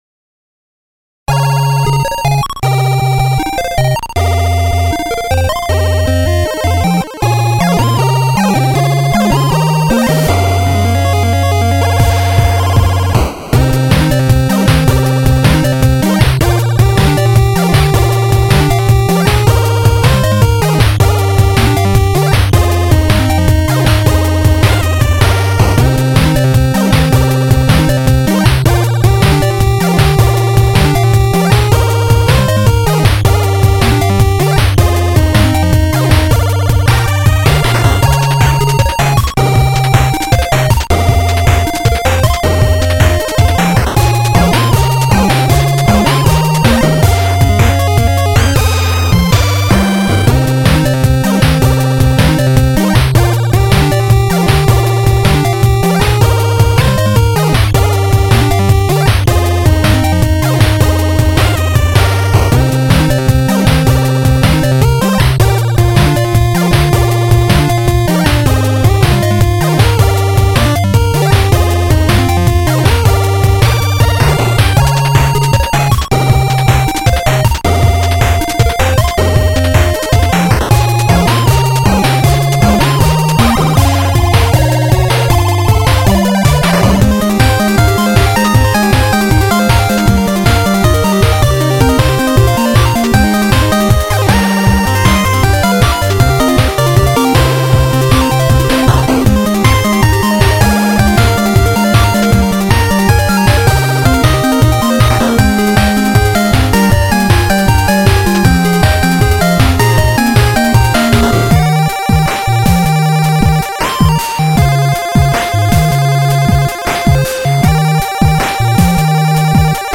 (2A03)